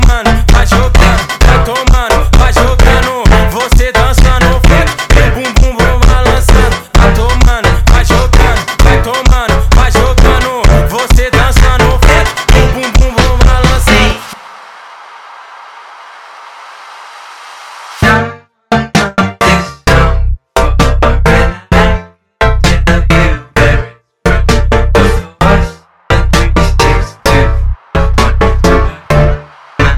Жанр: Электроника